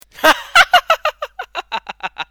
Childish laugh.